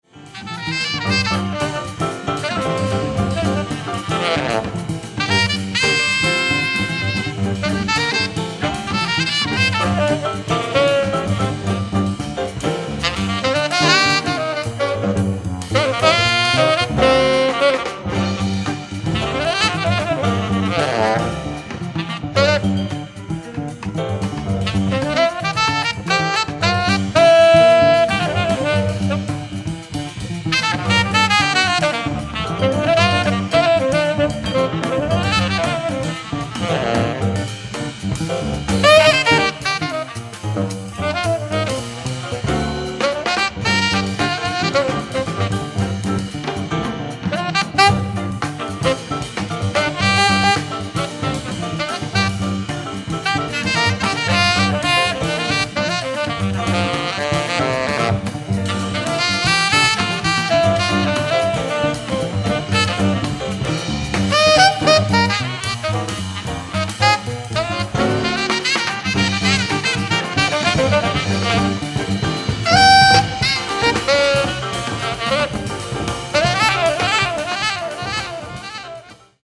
ライブ・アット・フィルハーモニー、ベルリン、ドイツ 11/05/1977
※試聴用に実際より音質を落としています。